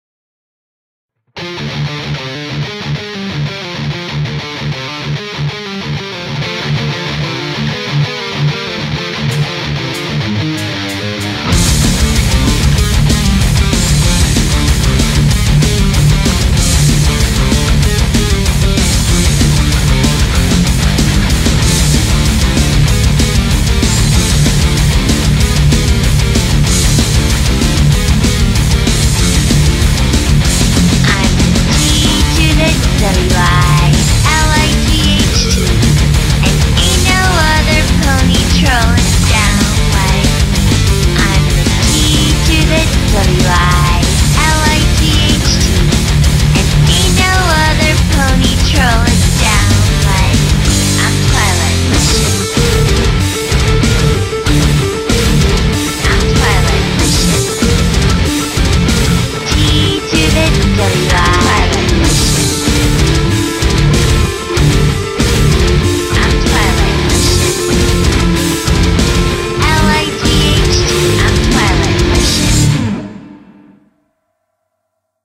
genre:metal